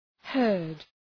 Shkrimi fonetik {hɜ:rd}